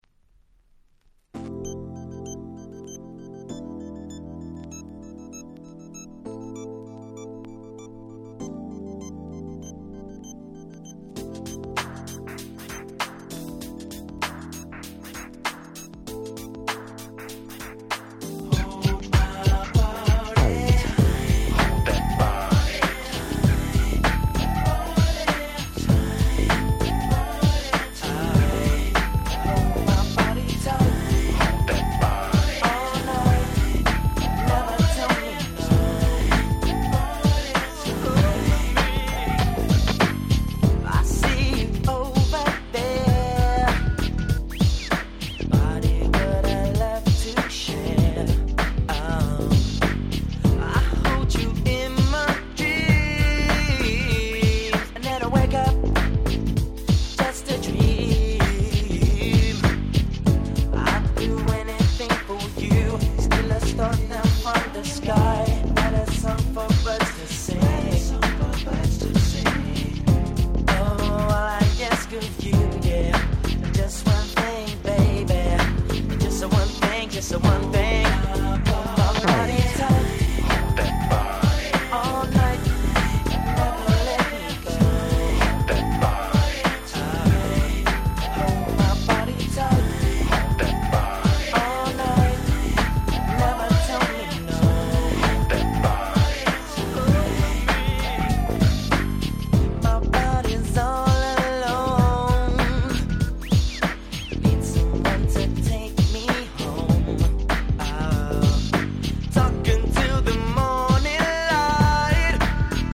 95' Nice UK R&B !!